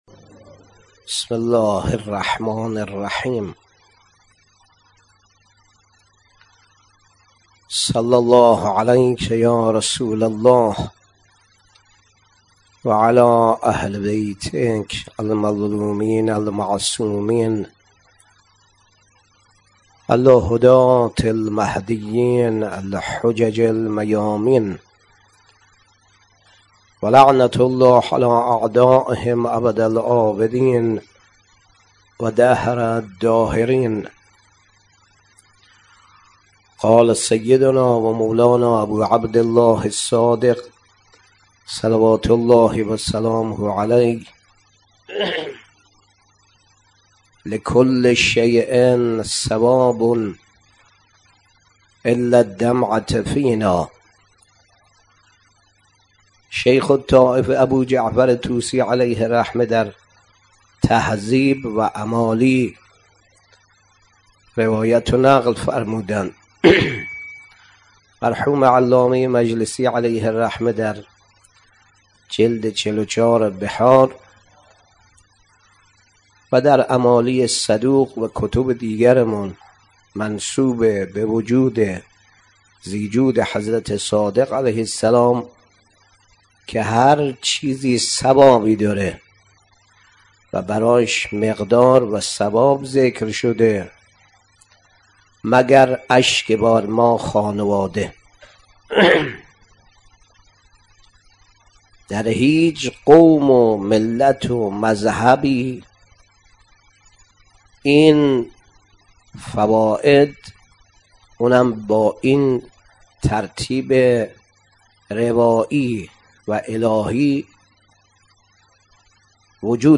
شب ششم محرم 97 - بیت آیةالله صافی گلپایگانی - سخنرانی